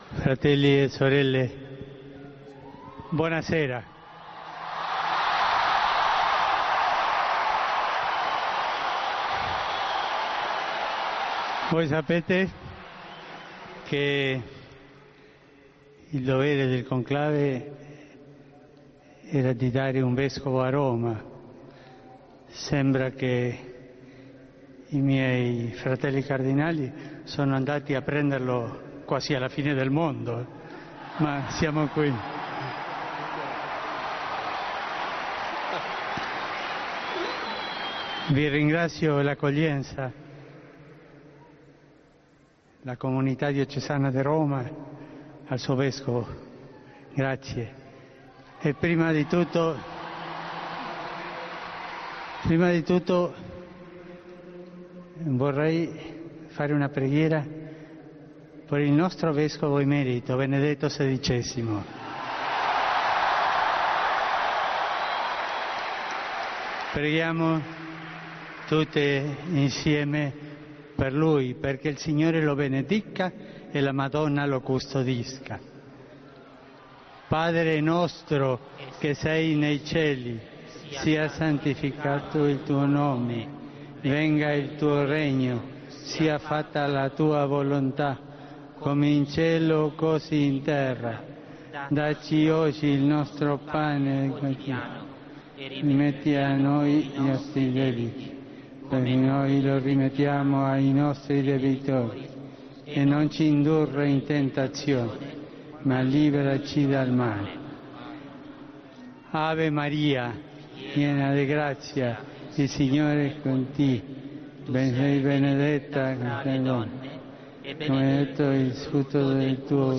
El primer discurso de Bergoglio como Papa: Han ido a buscarlo casi al fin del mundo